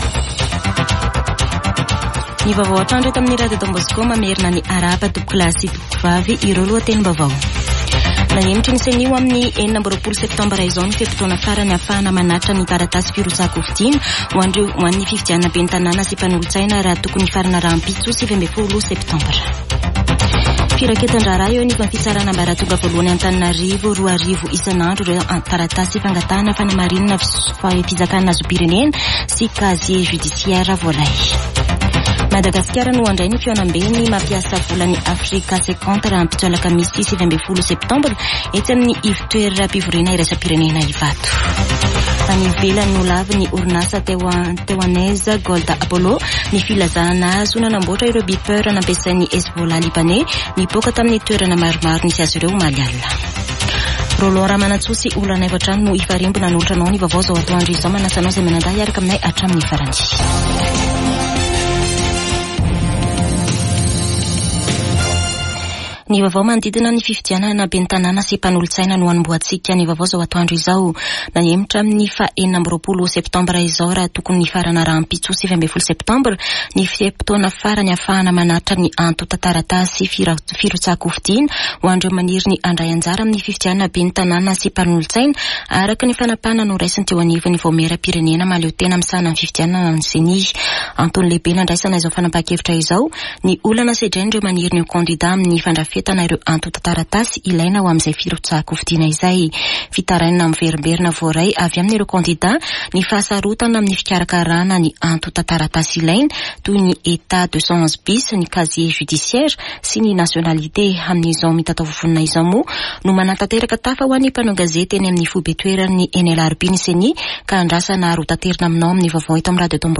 [Vaovao antoandro] Alarobia 18 septambra 2024